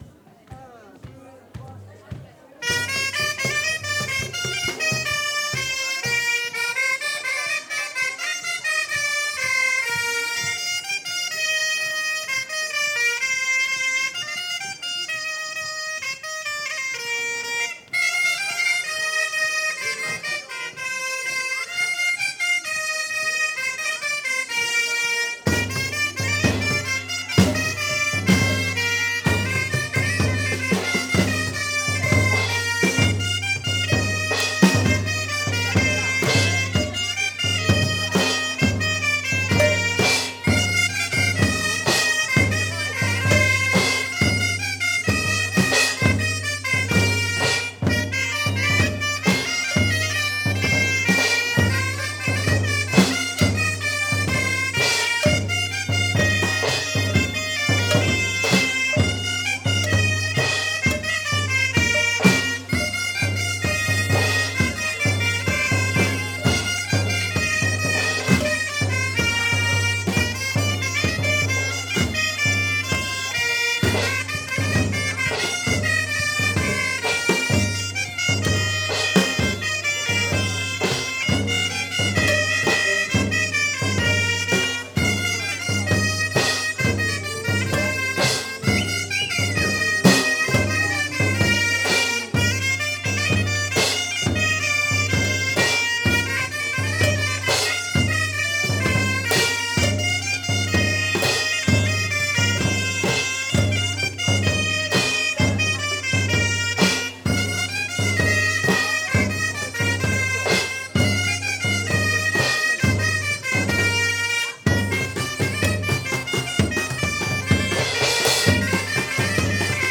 01_tour_sur_le_dret-bombardes_percussions.mp3